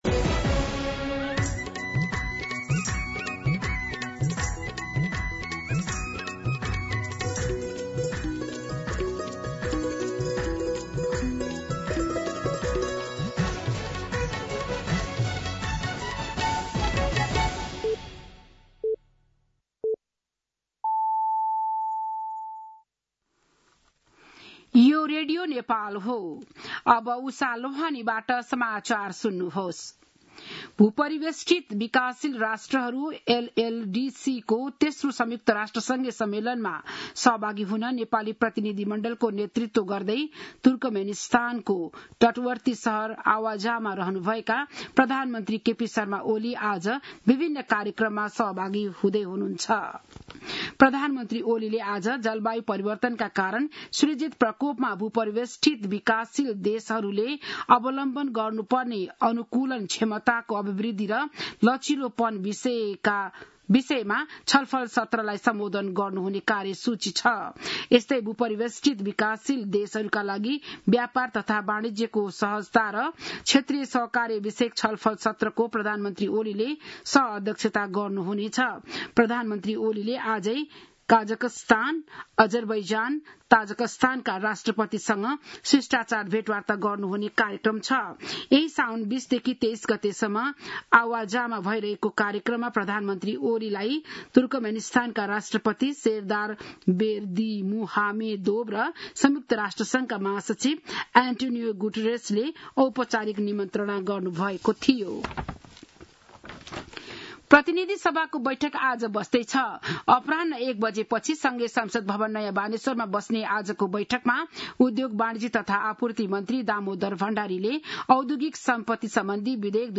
बिहान ११ बजेको नेपाली समाचार : २१ साउन , २०८२